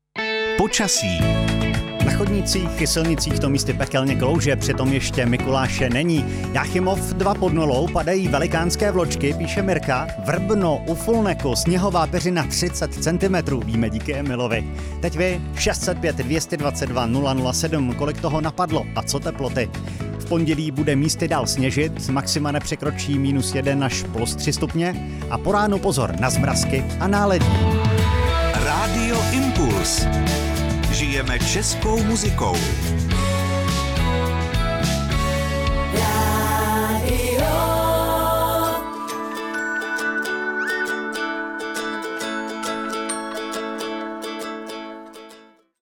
Rádio Impuls – počasí